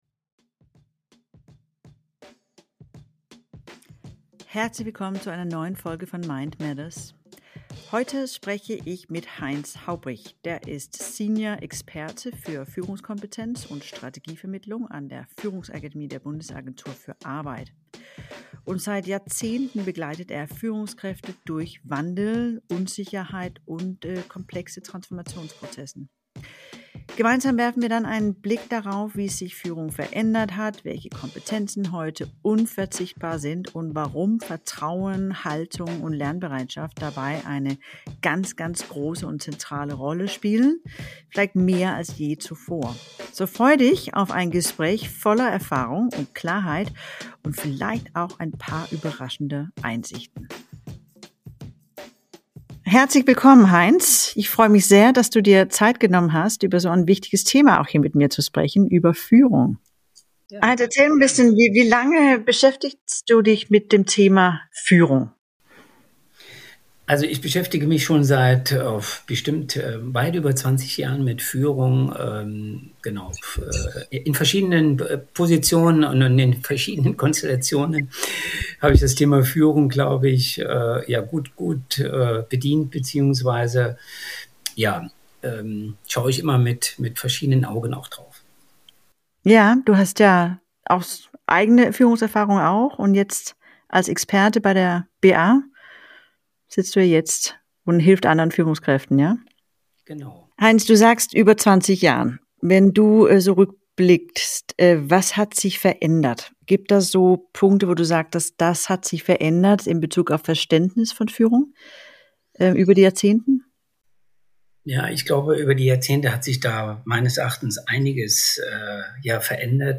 Wie hat sich Leadership über die Jahrzehnte verändert? Welche Rolle spielen Vertrauen, Haltung und Lernbereitschaft – und wo scheitern Führungskräfte heute am häufigsten? Ein kompakter, ehrlicher Austausch über Führung im Umbruch und die Herausforderungen der nächsten Jahre.